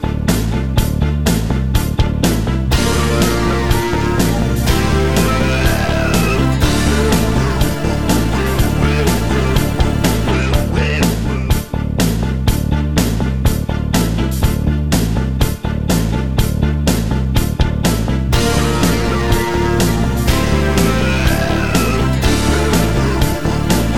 (no BVs) - Two Semitones Down Rock 4:03 Buy £1.50